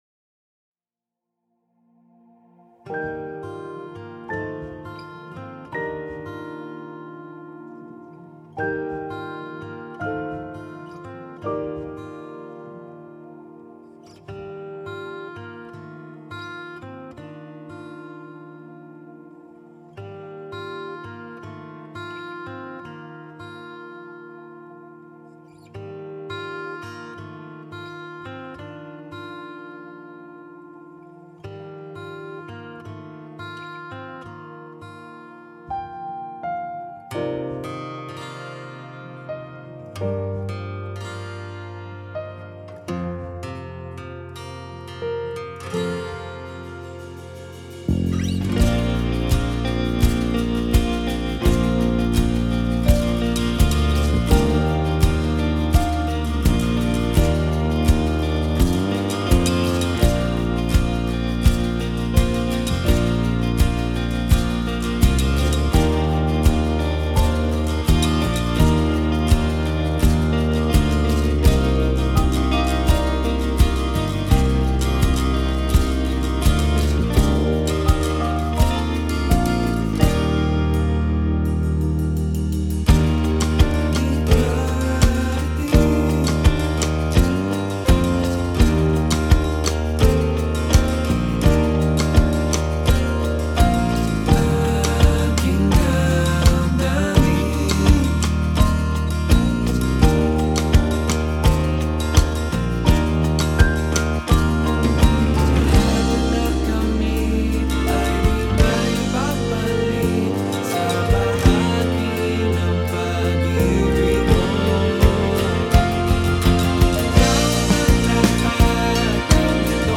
Minus One